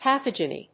音節pa・thog・e・ny 発音記号・読み方
/pəθάdʒəni(米国英語)/